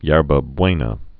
(yârbə bwānə, yûrbə)